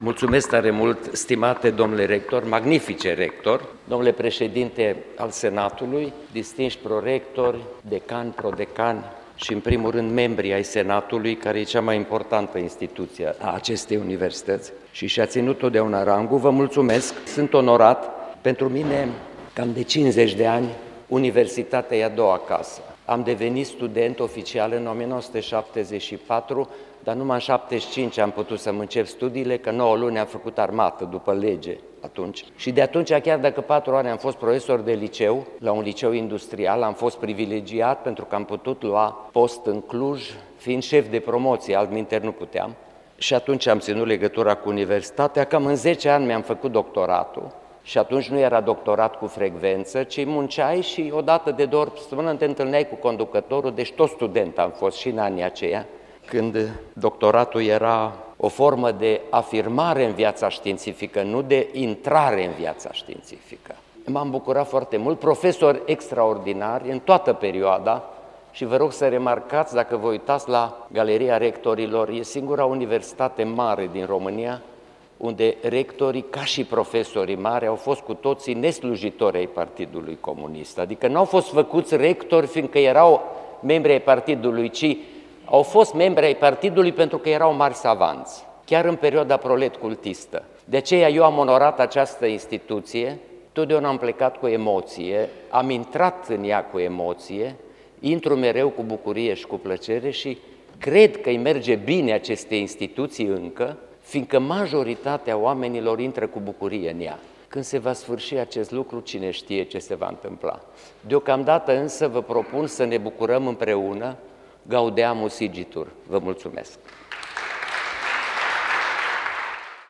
Remiterea oficială a înscrisului constatator va avea loc în cadru festiv, în deschiderea reuniunii Senatului, desfășurată în Aula Magna a Universității.
AUDIO: Discurs de mulțumire al acad. Ioan-Aurel Pop, președintele Academiei Române